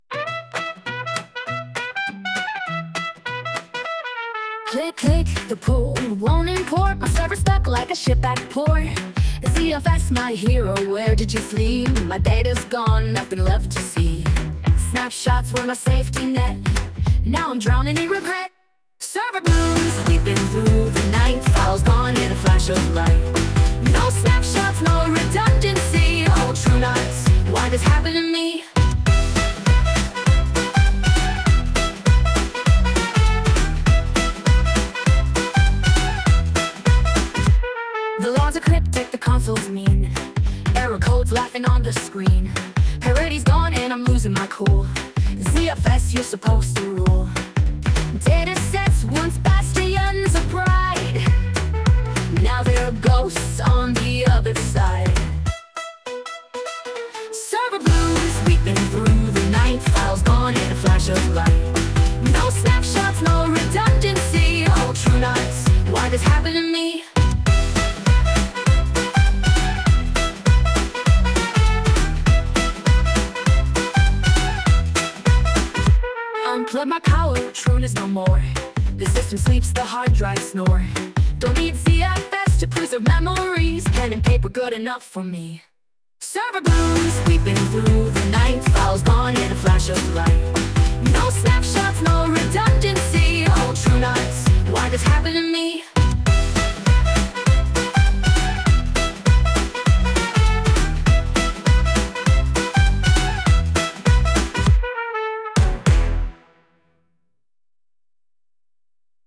I made this with Suno in about one minute.
I simply prompted Suno to make me an electro swing song about a server admin losing his files on TrueNAS, and to mention keywords such as ZFS and snapshots. I told it to add a saxophone and piano.